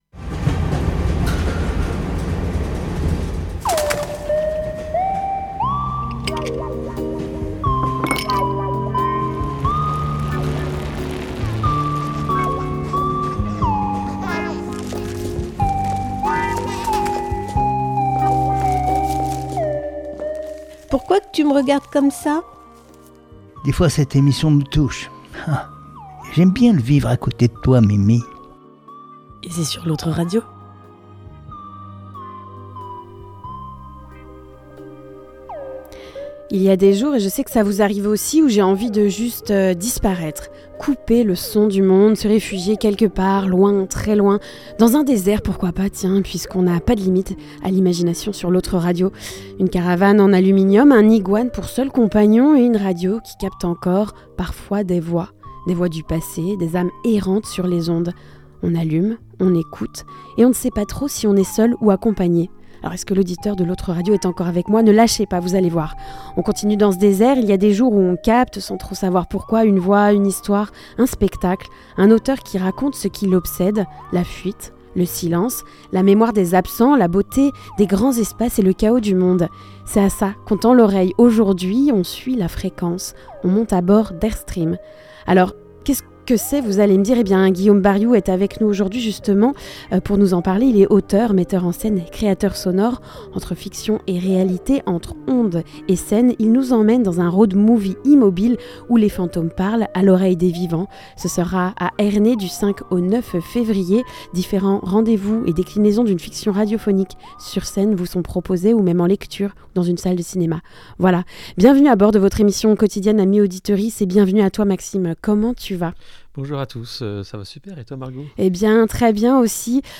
La petite annonce au Bout du Fil C'est le troisième et dernier épisode de notre série de reportages au sein de la Brasserie L'Arborescence, à Saint-George-Buttavent.